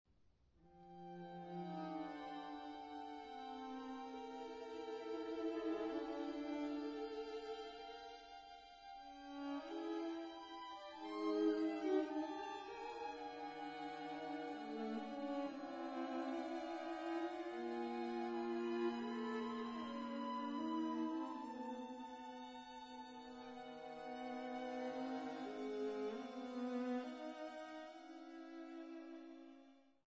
Cello
Viola
Violin
St John's Church, Loughton